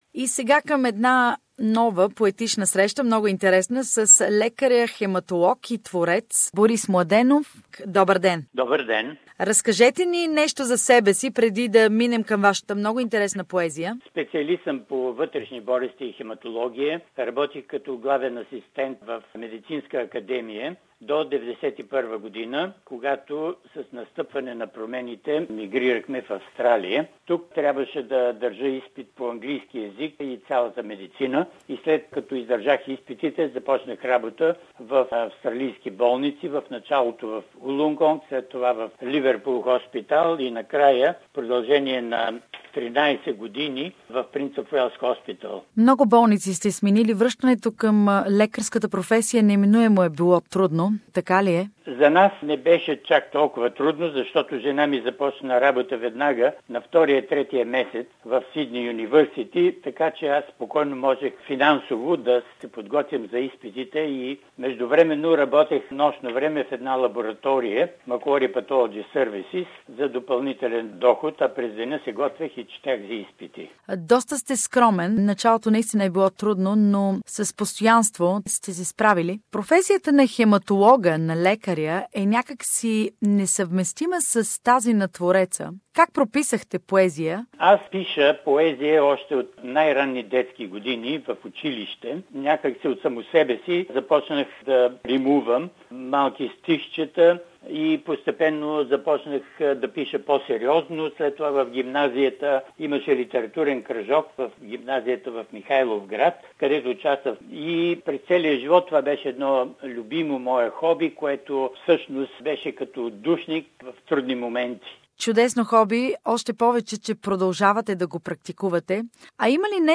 За поезията на един лекар. Представяне на стихосбирката "I call Australia home" сонети за Австралия, нейната природа, хора и живота. Интервю